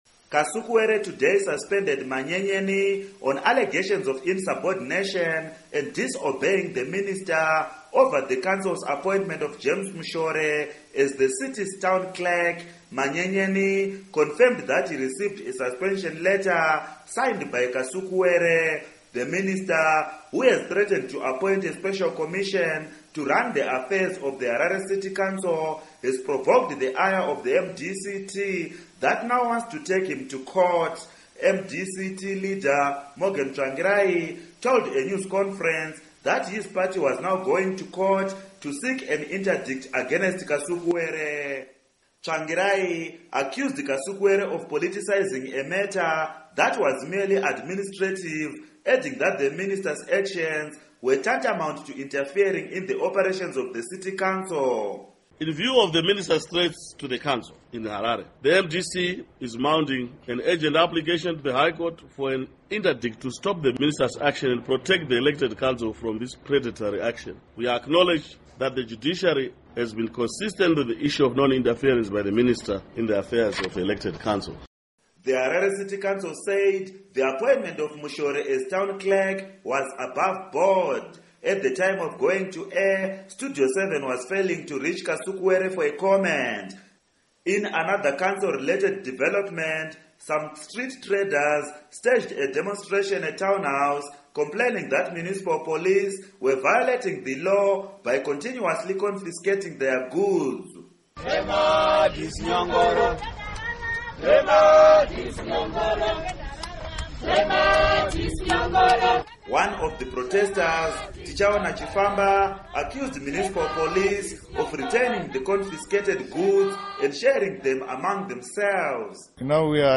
Report on MDC-T Harare Council